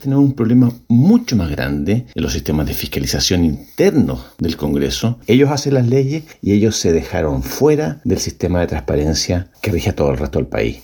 Por otra parte, el expresidente del Consejo para la Transparencia, Francisco Leturia, cuestionó la pasividad del Congreso frente a este tipo de situaciones y señaló que espera que puedan regirse bajo el sistema de transparencia.